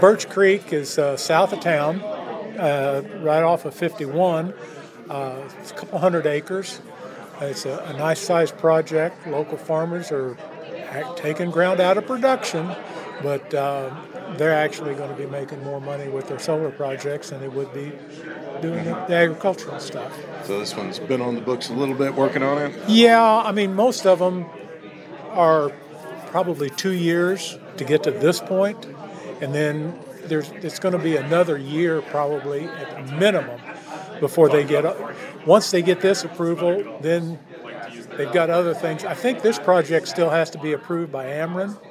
At their meeting on Tuesday night, the Fayette County Board approved a new solar project.  Fayette County Board member Merrell Collins explains the details and the area of the project.